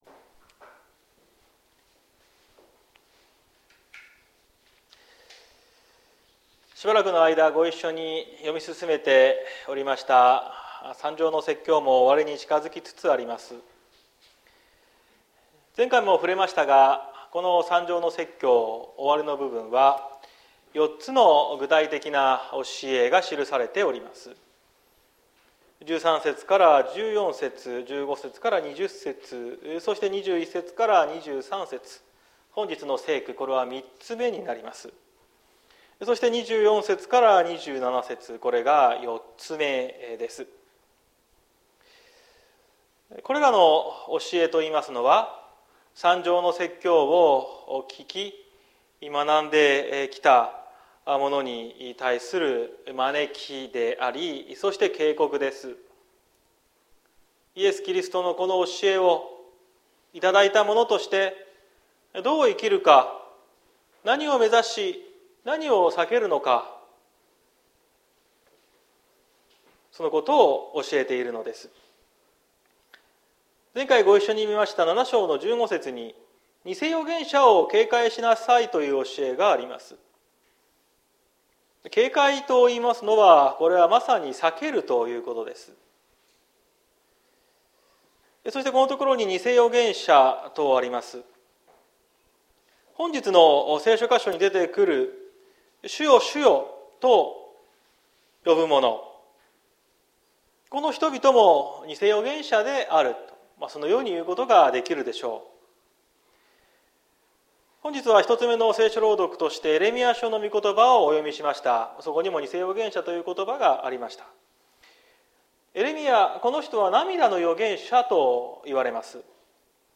2023年05月07日朝の礼拝「天の国に入る者」綱島教会
綱島教会。説教アーカイブ。